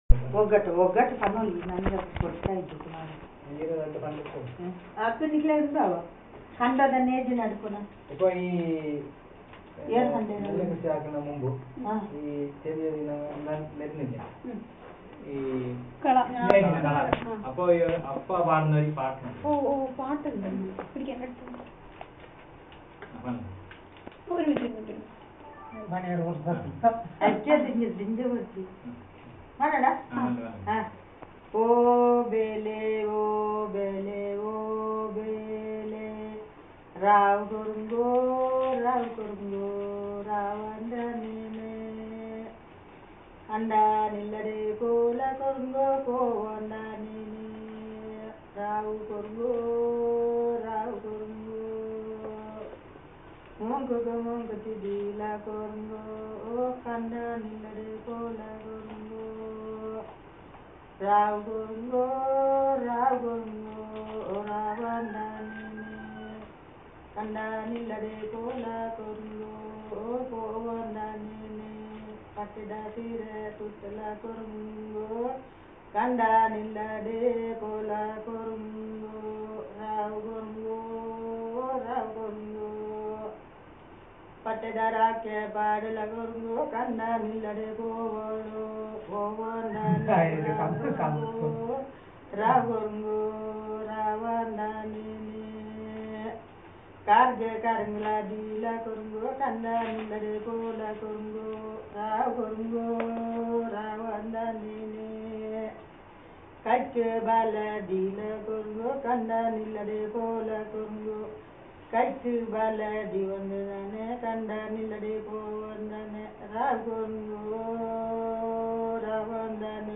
Traditional song about Weeding